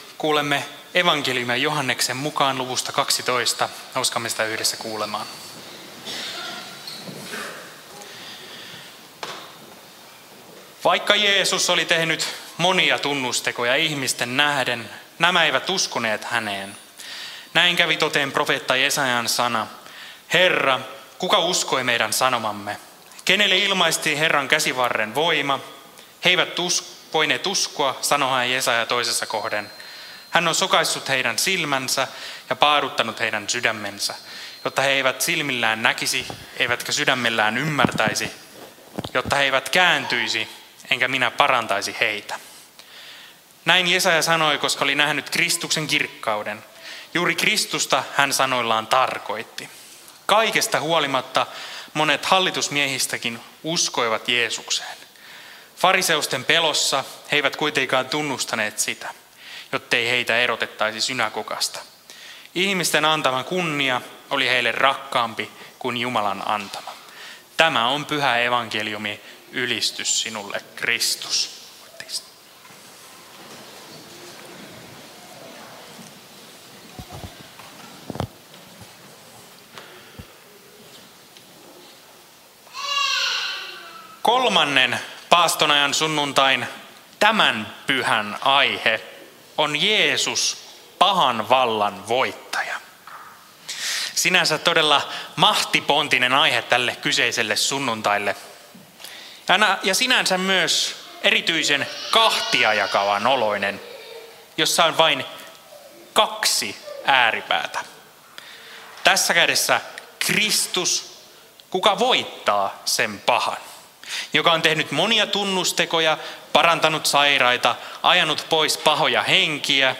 saarna Kälviän tuomasmessussa 3. paastonajan sunnuntaina